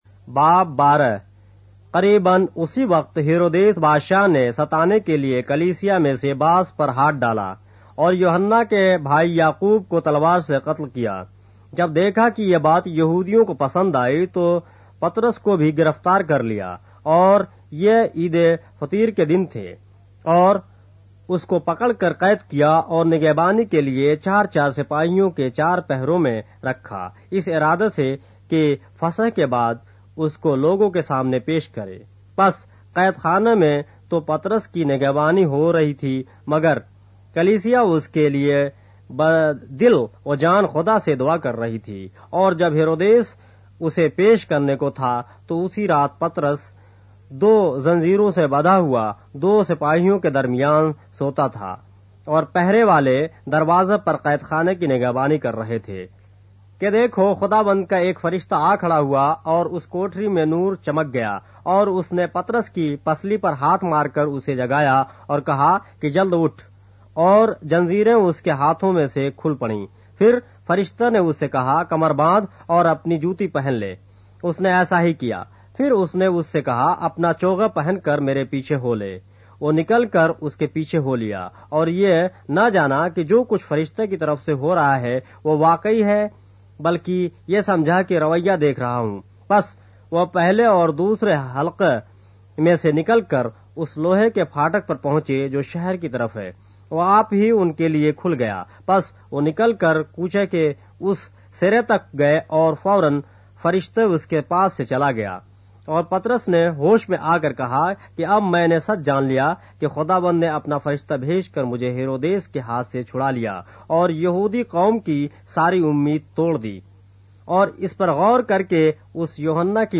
اردو بائبل کے باب - آڈیو روایت کے ساتھ - Acts, chapter 12 of the Holy Bible in Urdu